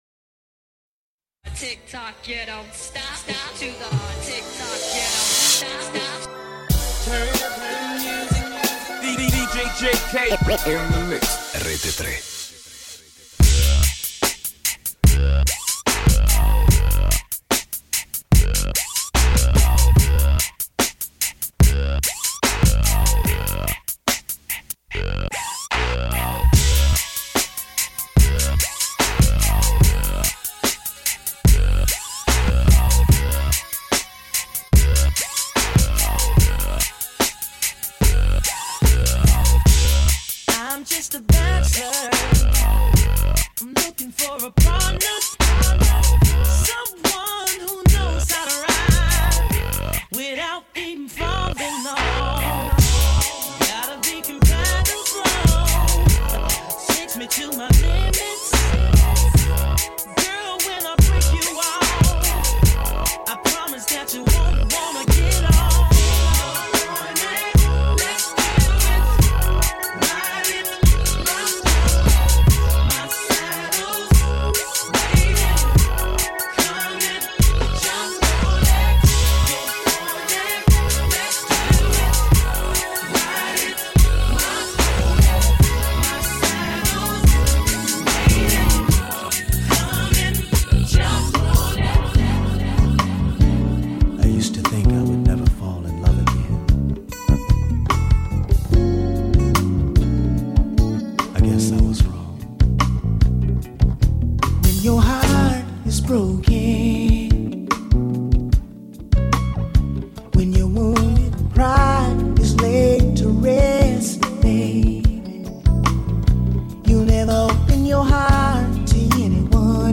BLACK RAP / HIP-HOP